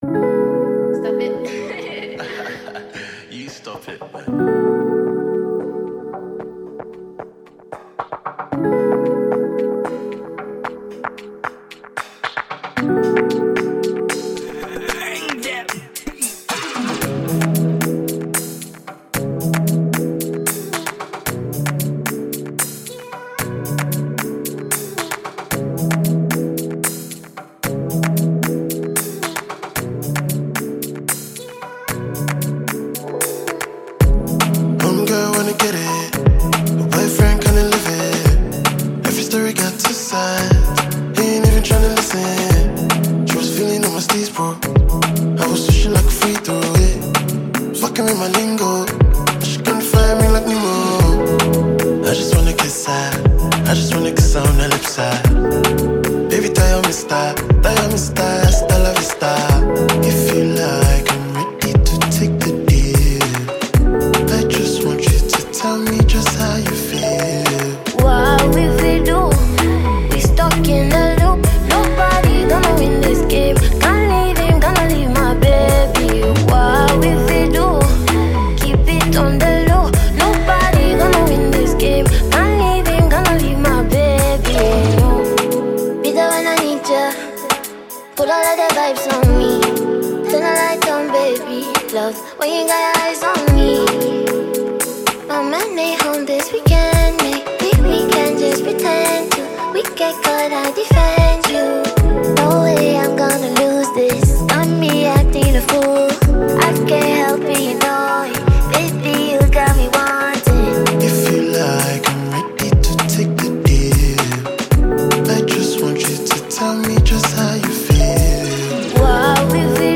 Nigerian Alte singer and songwriter